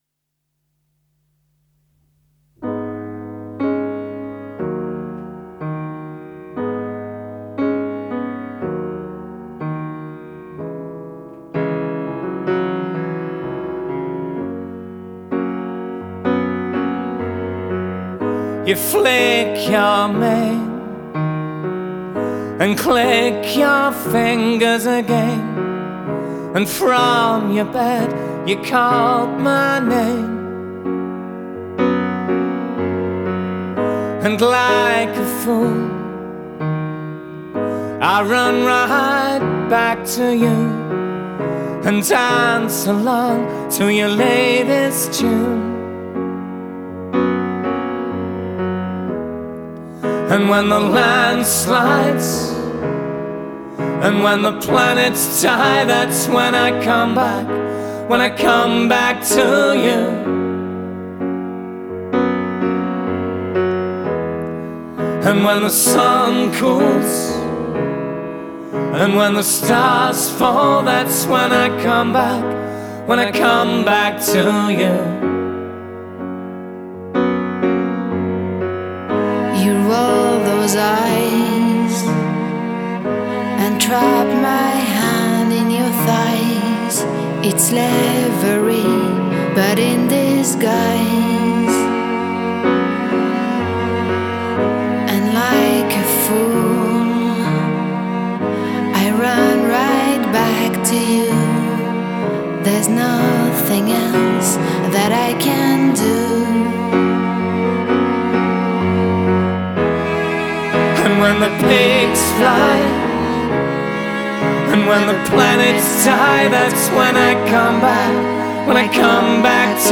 Indie Rock
آلترنتیو راک